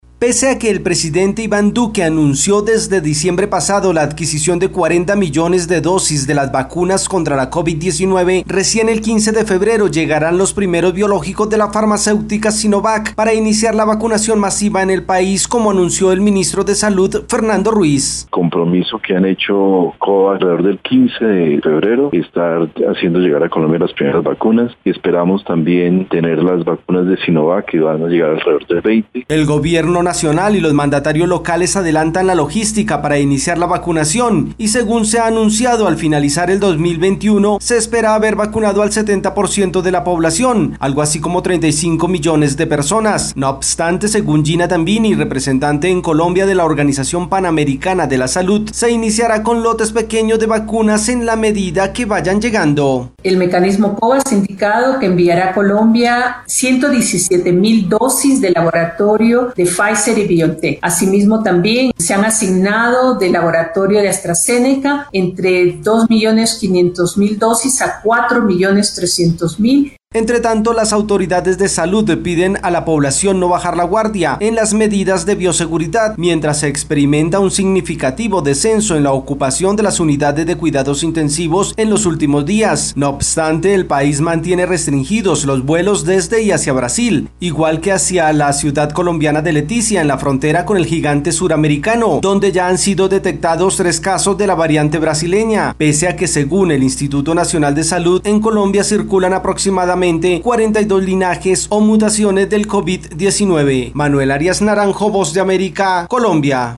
Colombia recibirá en 15 días las primeras vacunas contra el COVID-19 y tiene previsto iniciar el 20 de febrero la vacunación masiva. Desde Colombia informa el corresponsal de la Voz de América